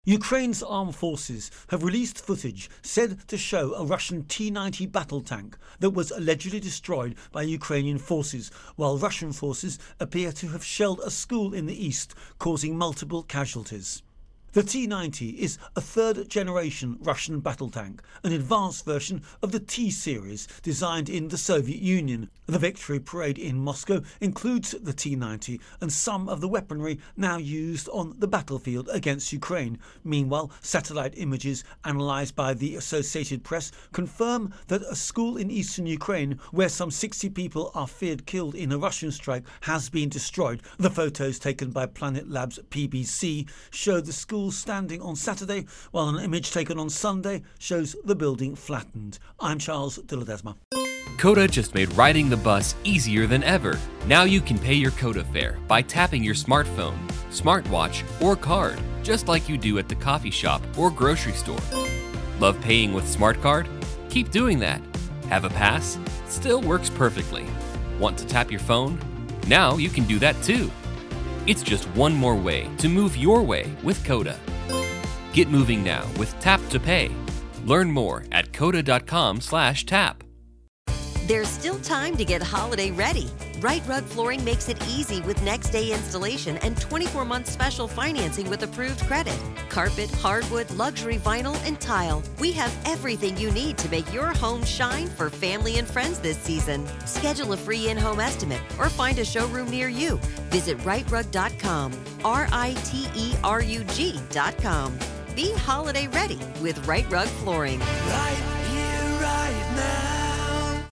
Russia-Ukraine-War-Tank Hit Intro and Voicer